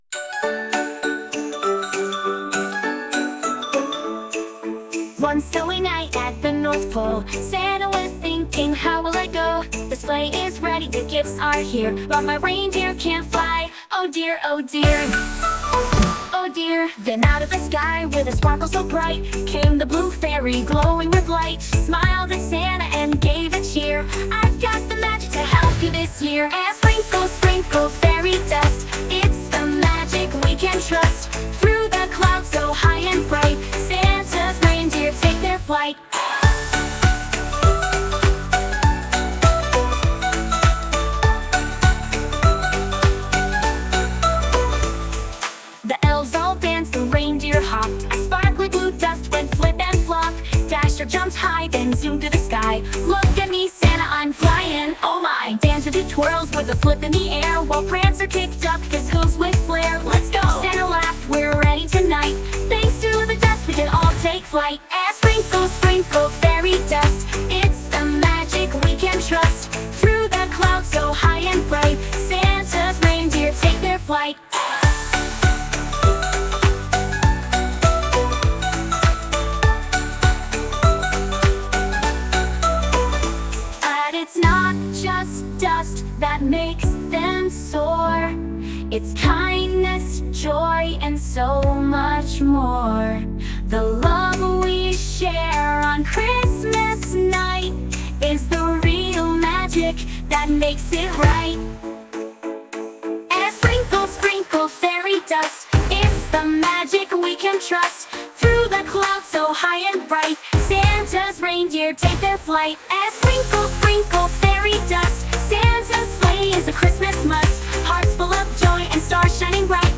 A SantaAZ Original Song
An Upbeat Christmas Song for Elementary School Kids